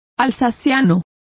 Complete with pronunciation of the translation of alsatian.